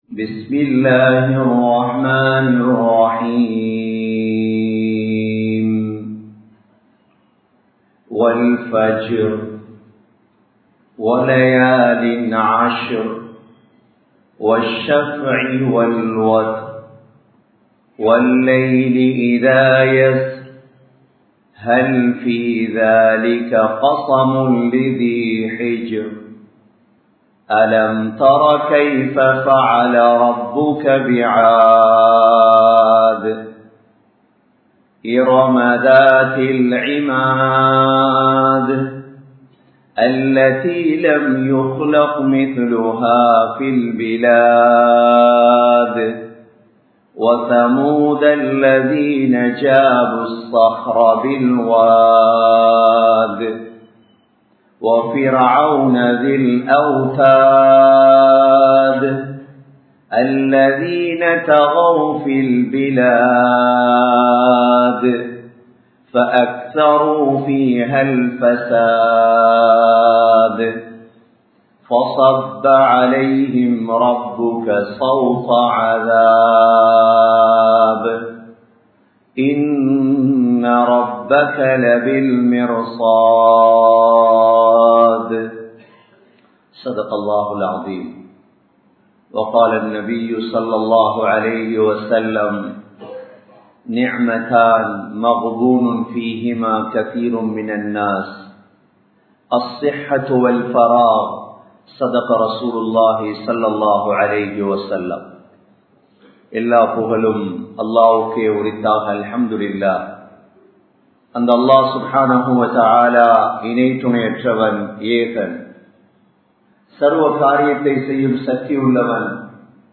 Muslimkalin Munneattram Ethil Ullathu? (முஸ்லிம்களின் முன்னேற்றம் எதில் உள்ளது?) | Audio Bayans | All Ceylon Muslim Youth Community | Addalaichenai
Colombo 11, Samman Kottu Jumua Masjith (Red Masjith)